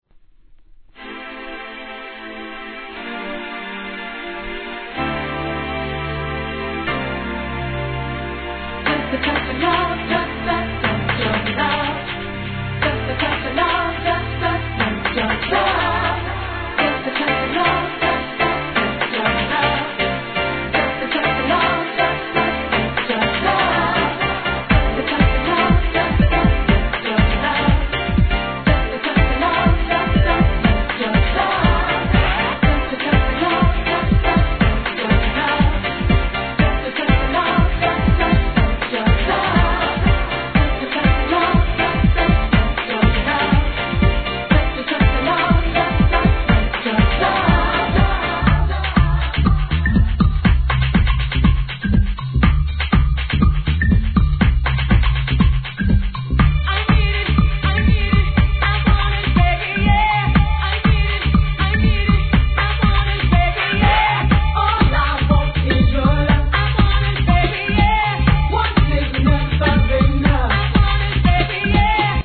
GARAGE MIX